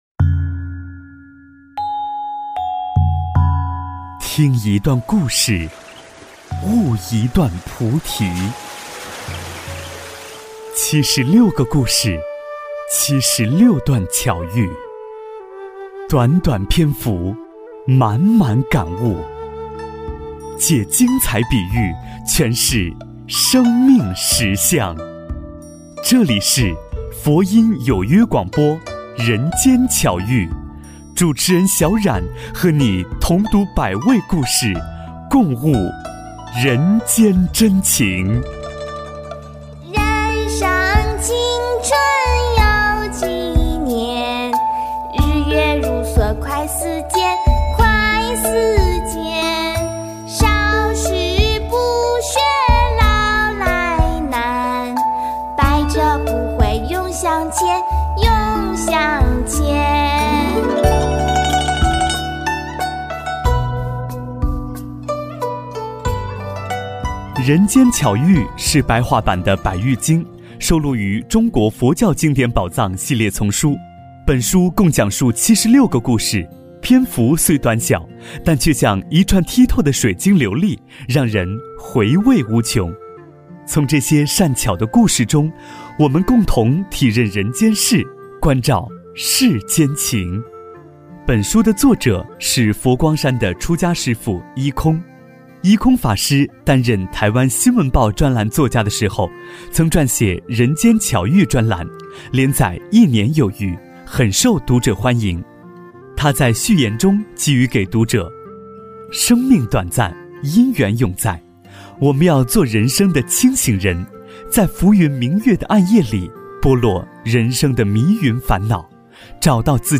五指的争议--有声佛书